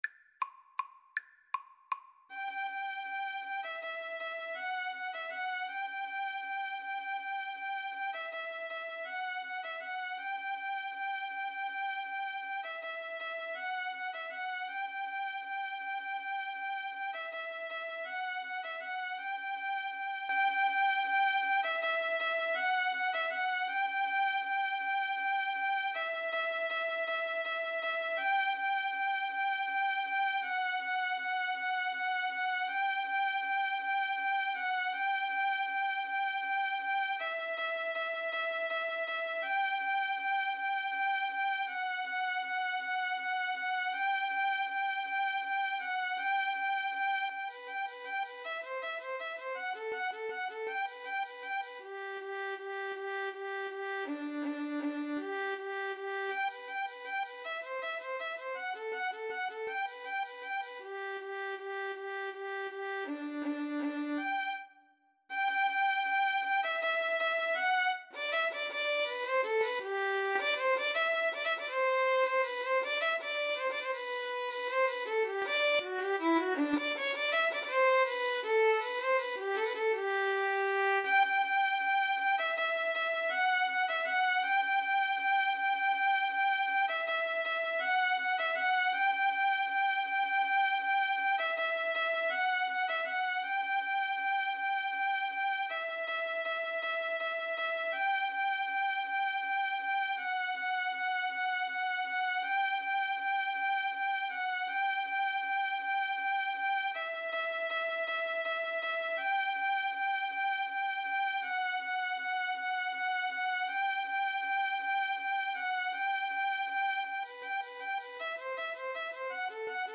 3/4 (View more 3/4 Music)
Allegro Vivo = 160 (View more music marked Allegro)
Classical (View more Classical Violin-Cello Duet Music)